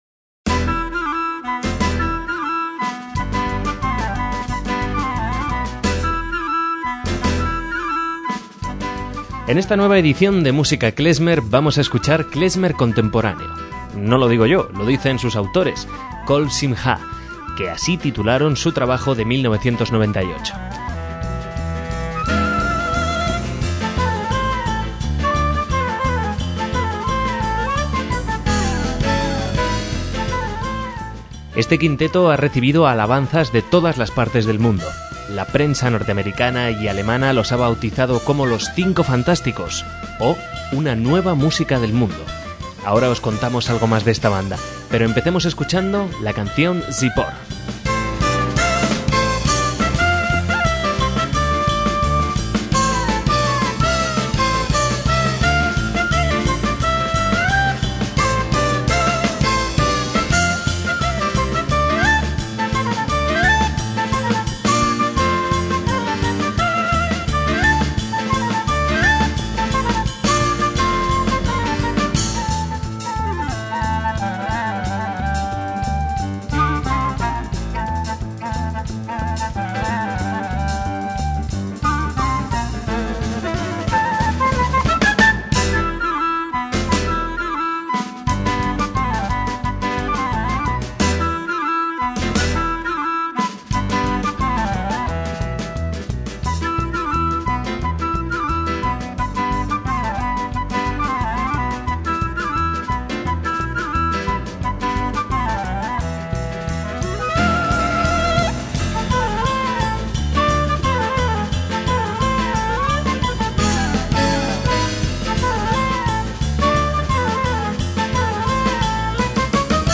MÚSICA KLEZMER
clarinete
flauta
piano